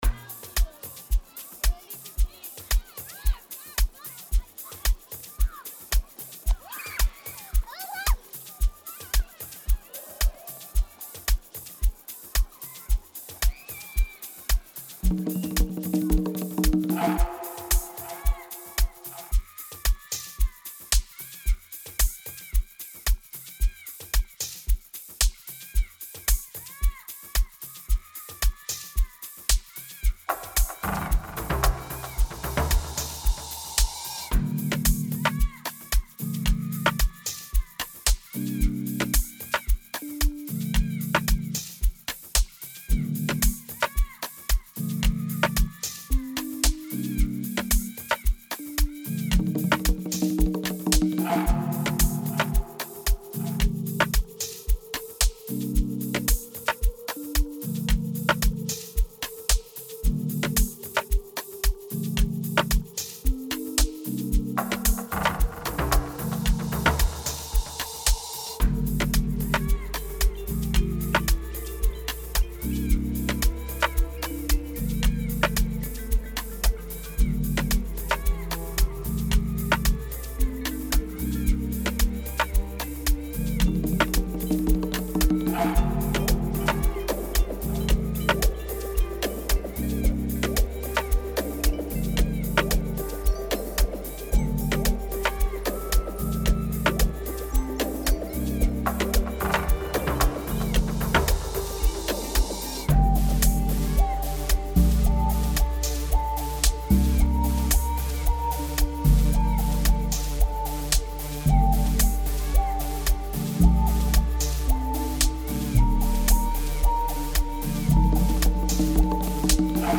Amapiano song